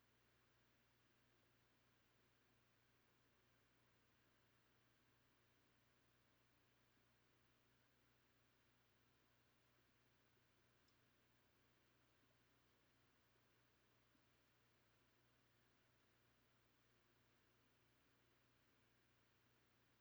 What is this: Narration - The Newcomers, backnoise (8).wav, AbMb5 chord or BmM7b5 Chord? backnoise (8).wav